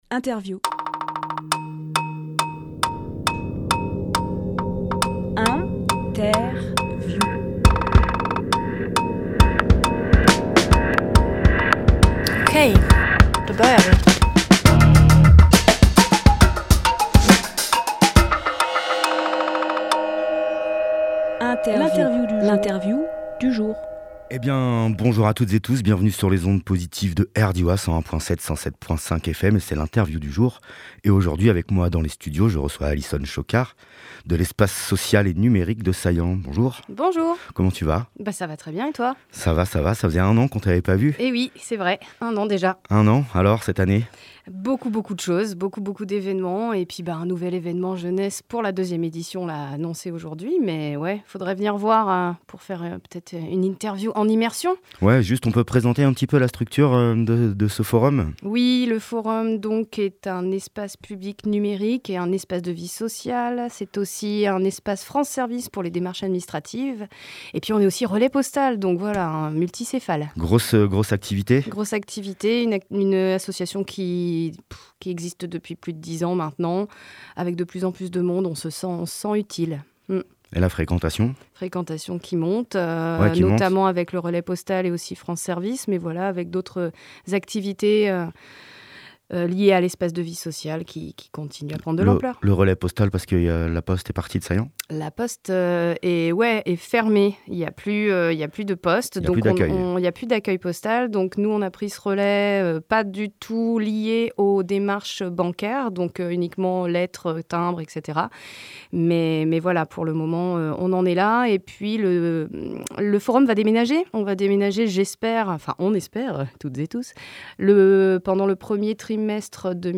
Emission - Interview Le Sourire du Crocodile #2, un festival jeune public à Saillans Publié le 30 juin 2023 Partager sur… Télécharger en MP3 Télécharger en MP3 Le Sourire du Crocodile #2 est un festival jeunesse qui se déroule à Saillans les 7 et 8 juillet 2023.